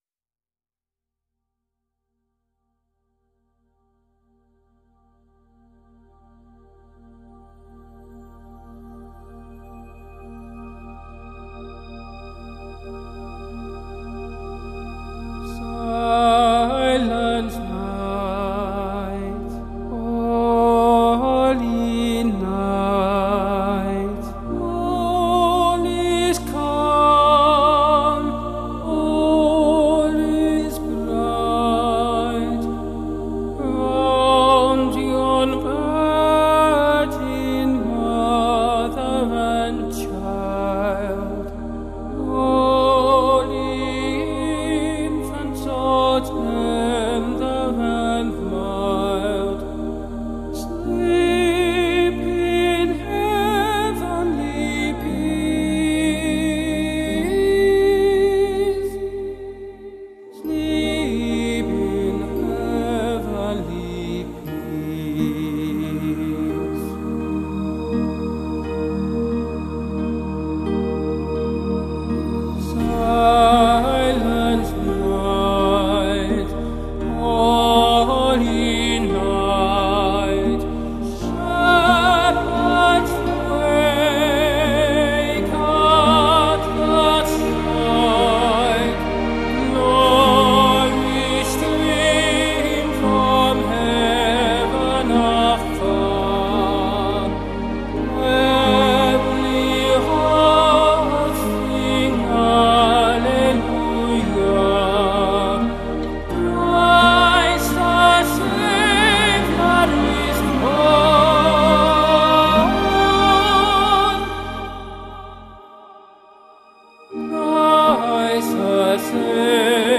His is a gentle voice. A kind one.